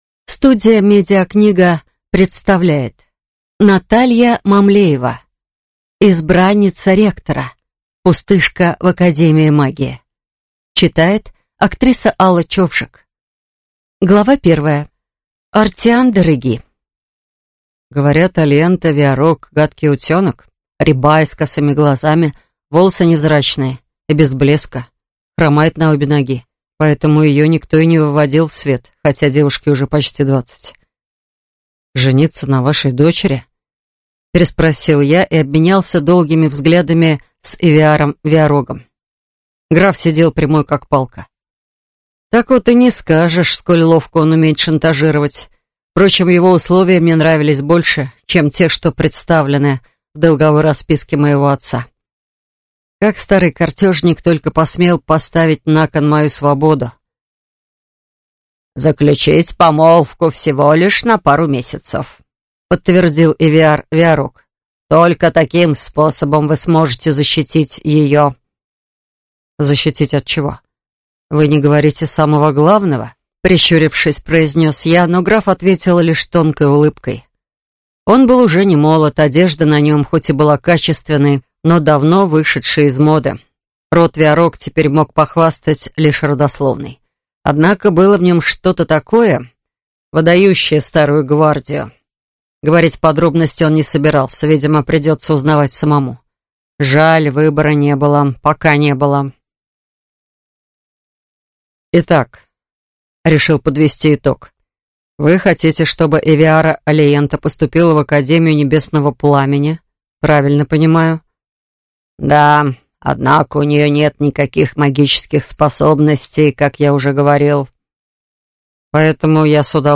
Аудиокнига Избранница ректора: «Пустышка» в академии магии | Библиотека аудиокниг